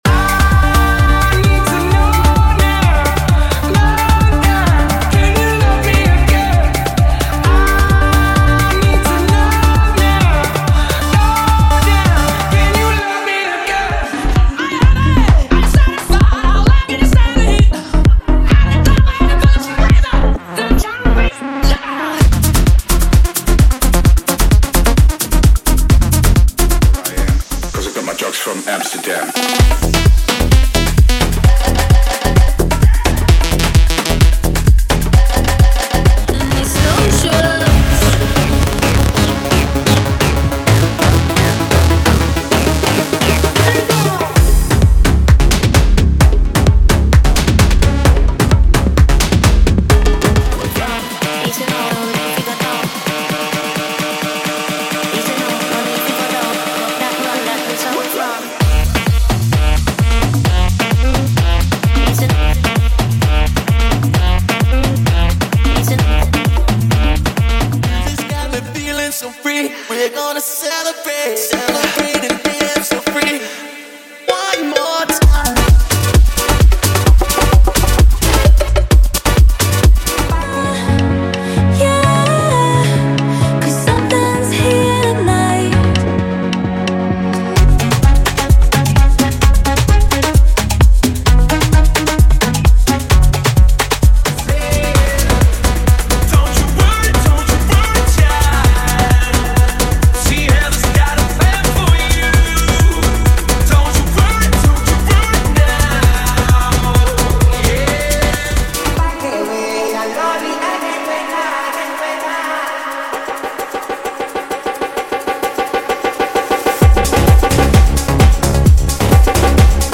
Sem Vinhetas